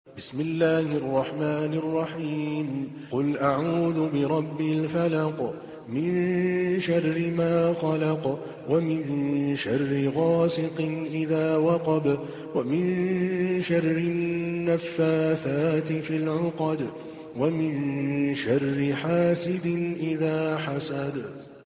تحميل : 113. سورة الفلق / القارئ عادل الكلباني / القرآن الكريم / موقع يا حسين